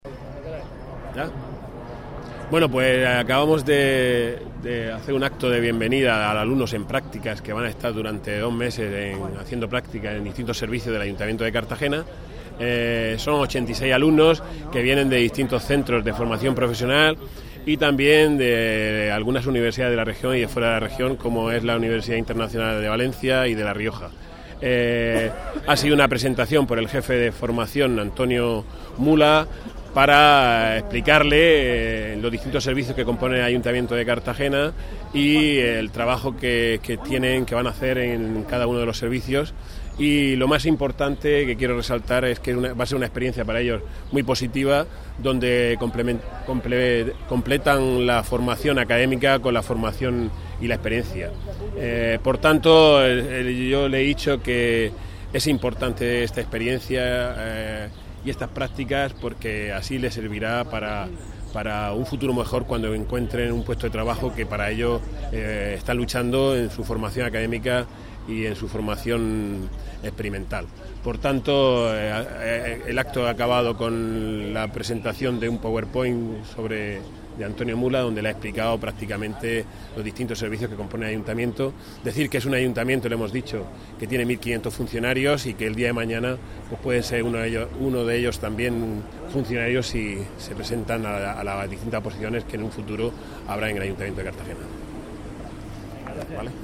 El Ayuntamiento de Cartagena ha dado la bienvenida hoy 7 de mayo en las instalaciones municipales del antiguo Parque de Artillería al alumnado de centros de formación y universidades que están realizando prácticas en varios departamentos del consistorio desde el mes de febrero y hasta el verano.